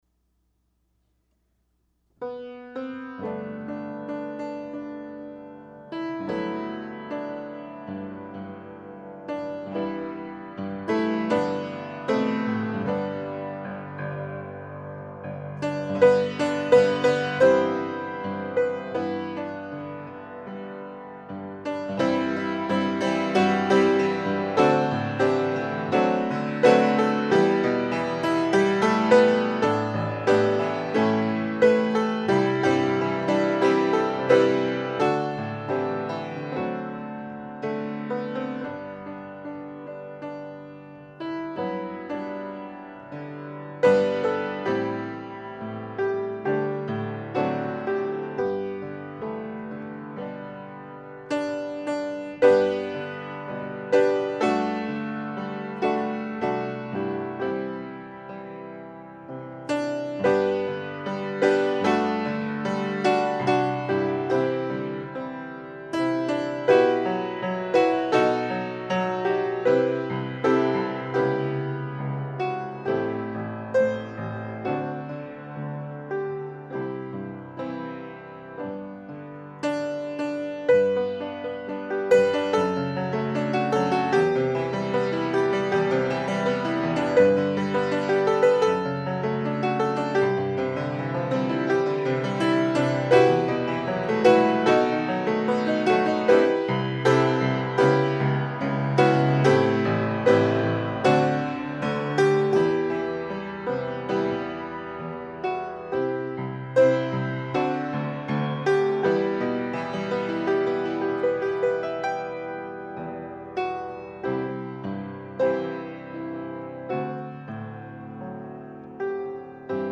Hymns, Choruses and Songs for Worship (mp3 files)